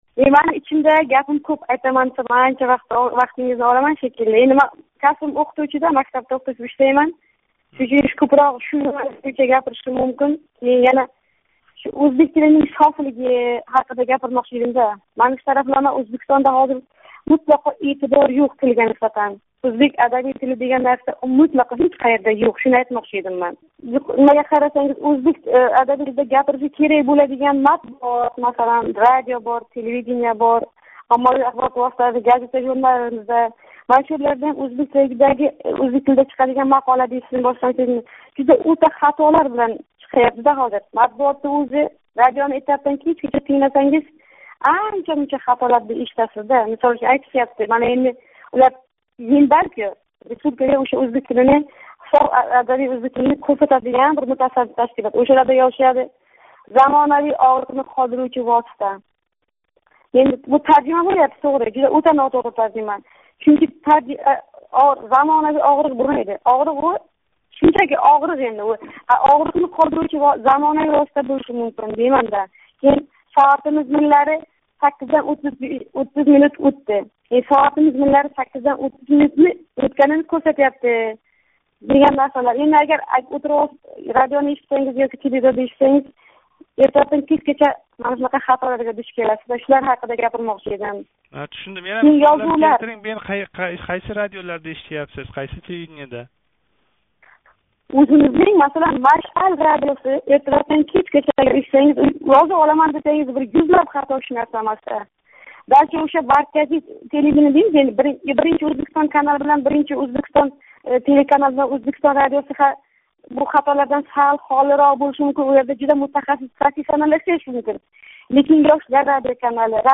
Мустақиллик арафасида қўнғироқ қилган оддий ўзбекистонликлардан бири халқ орасида байрам шукуҳи сезилмаётганидан гапирди.